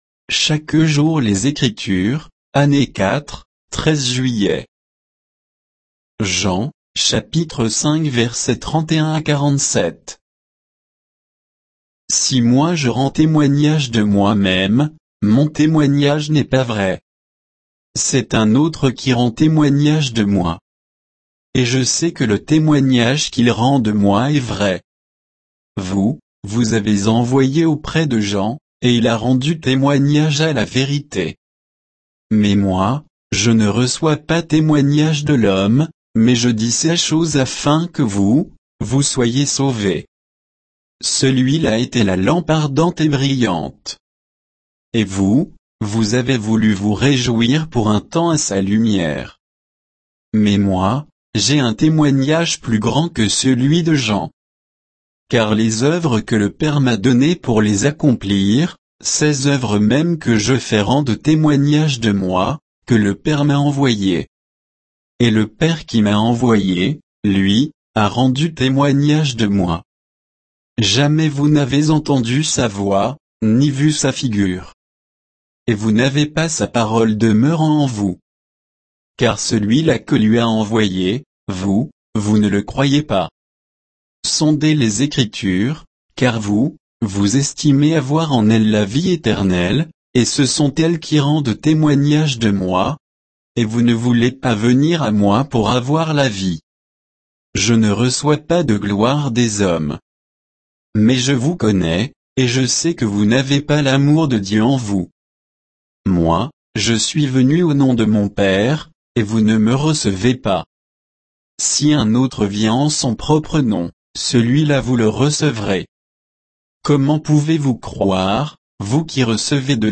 Méditation quoditienne de Chaque jour les Écritures sur Jean 5